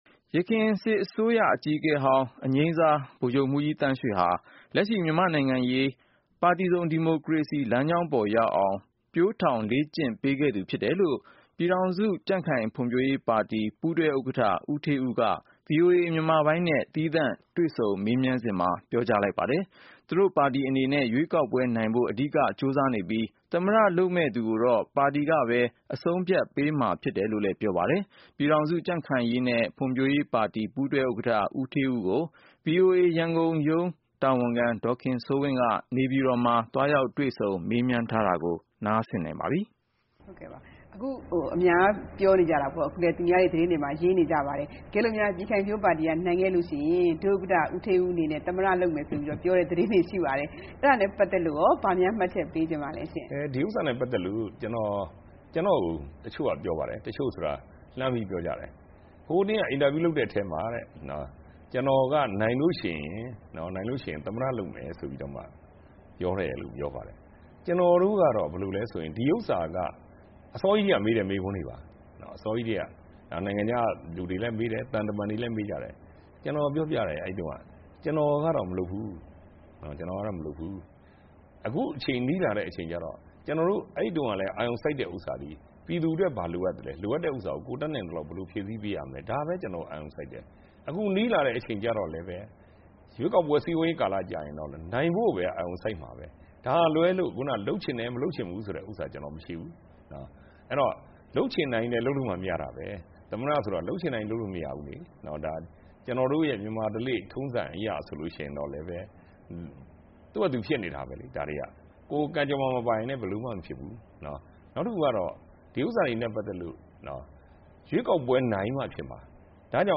ပြည်ခိုင်ဖြိုး ပူးတွဲဥက္ကဋ္ဌနဲ့ မေးမြန်းခန်း